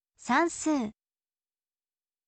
sansuu